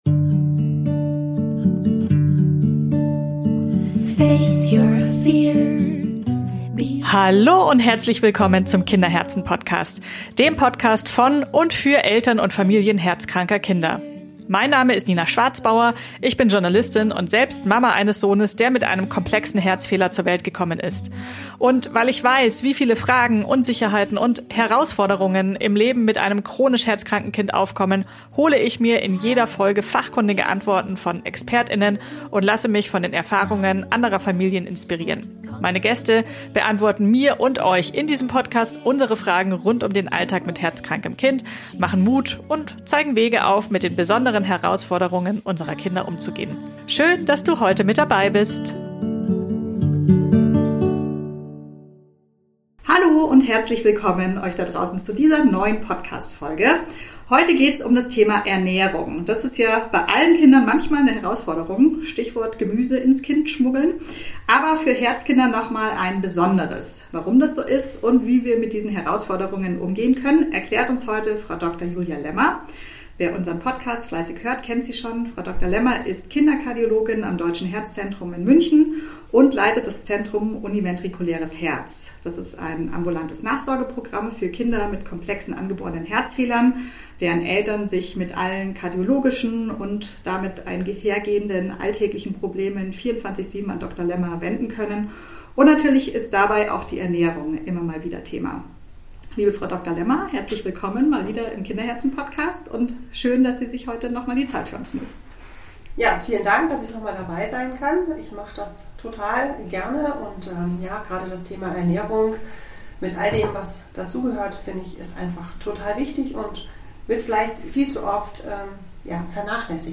Beschreibung vor 2 Monaten In dieser Folge sprechen wir über ein Thema, das viele Familien mit herzkranken Kindern täglich begleitet: Ernährung. Ich spreche mit der Kinderkardiologin